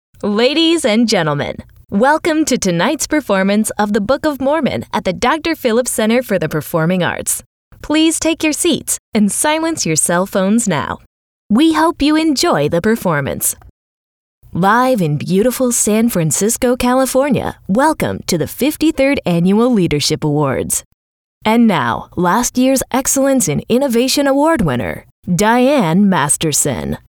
Live Announce - Female Voice of God (VOG)
Pre-recorded or live, in-person voiceover talent for your event.
Show Intros